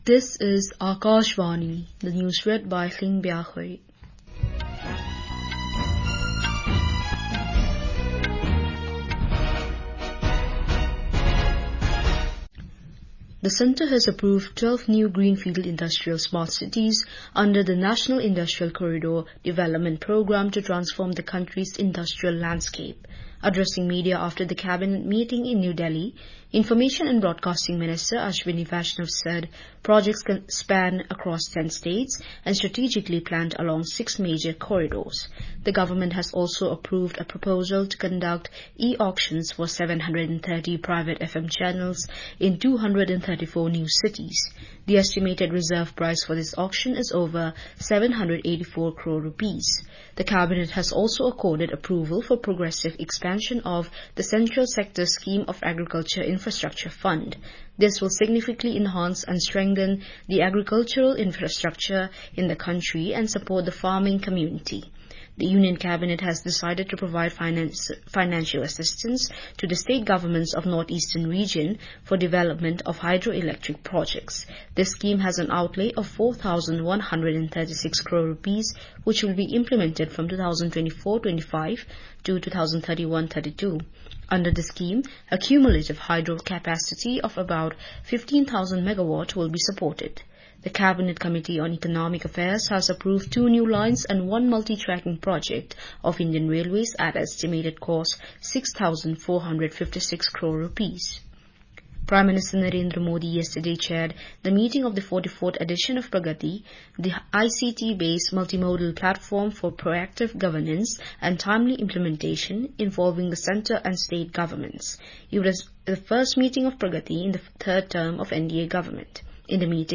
Hourly News